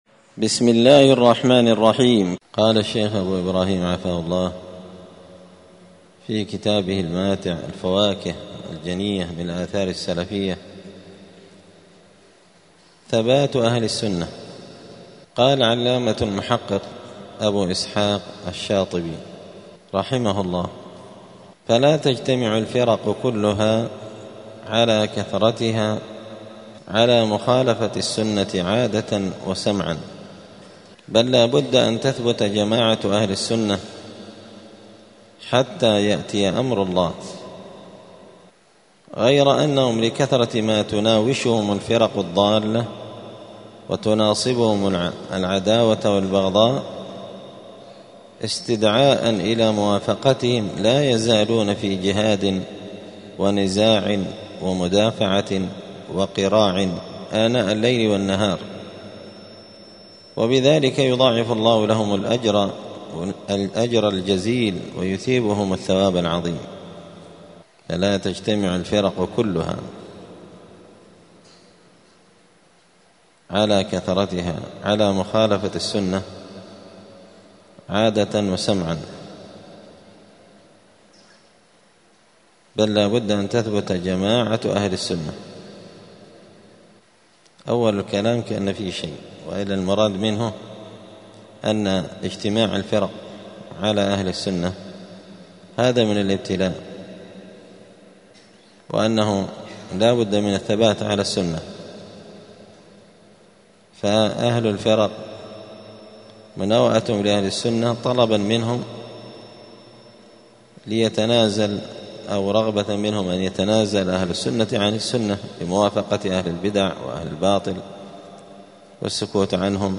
دار الحديث السلفية بمسجد الفرقان بقشن المهرة اليمن
الأحد 14 جمادى الآخرة 1446 هــــ | الدروس، الفواكه الجنية من الآثار السلفية، دروس الآداب | شارك بتعليقك | 49 المشاهدات